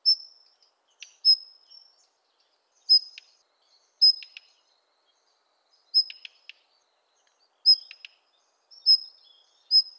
ヘルプ 詳細情報 ジョウビタキ 大きさ スズメくらい 季節 秋冬 色 オレンジ 特徴 全長15cmでスズメくらいの大きさ。